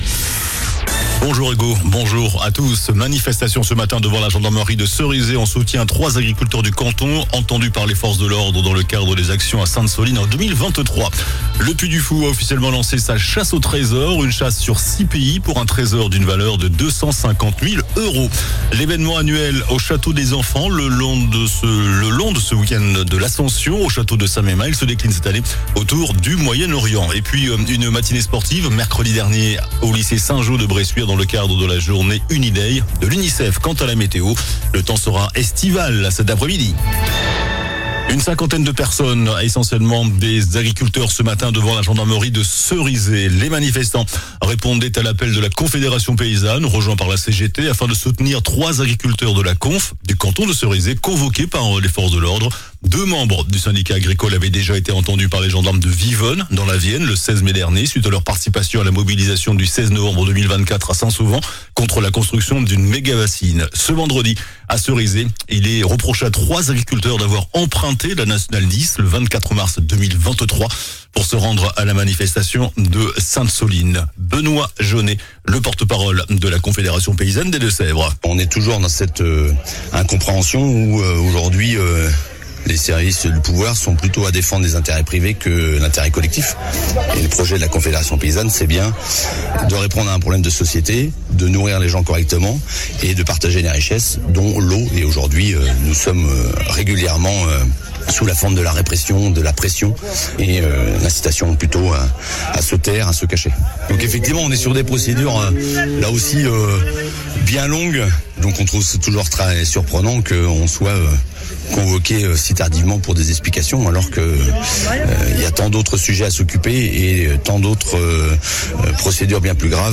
JOURNAL DU VENDREDI 30 MAI ( MIDI )